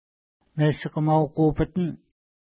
ID: 435 Longitude: -63.6251 Latitude: 54.0356 Pronunciation: meʃikəma:w-ku:pitan Translation: Big Lake Outlet Feature: lake outlet Explanation: The name refers to the outlet of lake Meshikamau (no 620).